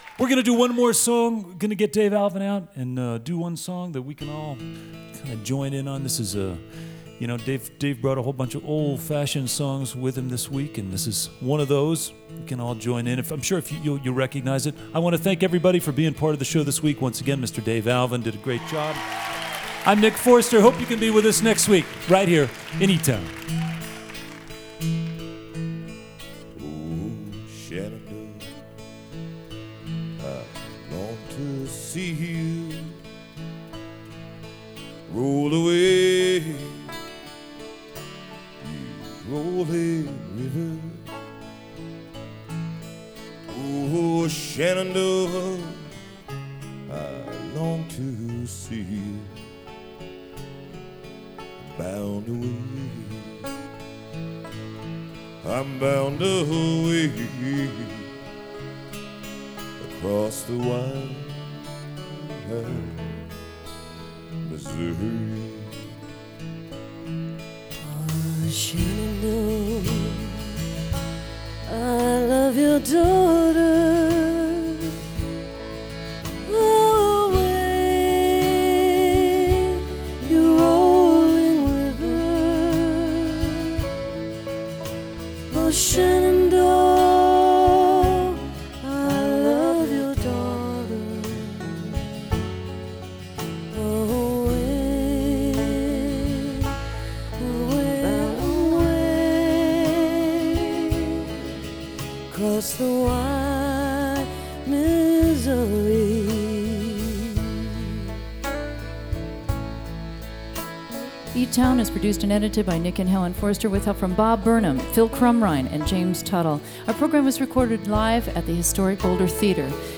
lifeblood: bootlegs: 2002-02-12: e town at boulder theatre - boulder, colorado (pre fm broadcast)
09. shenandoah (with dave alvin) (0:18)